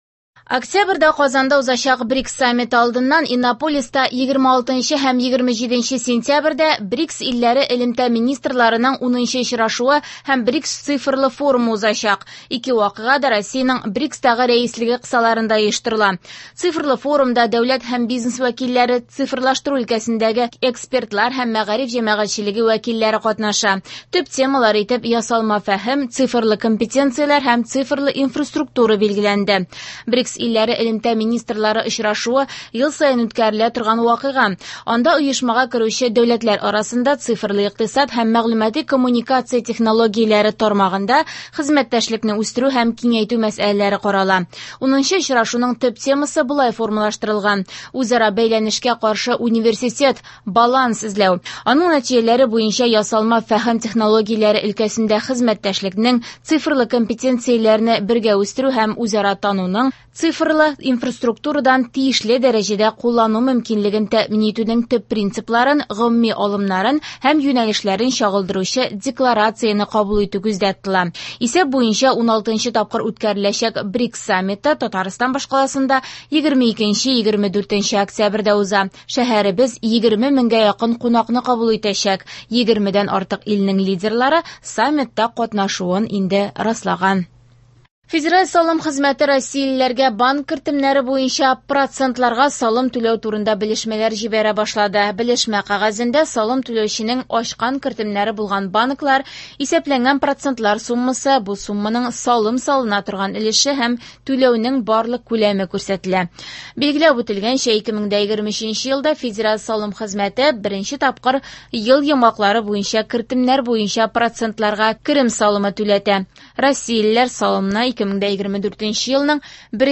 Яңалыклар (23.09.24)